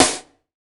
SNARE 062.wav